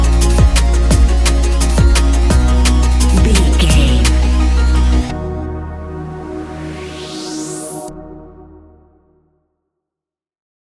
Ionian/Major
D♯
techno
trance
synths
synthwave
instrumentals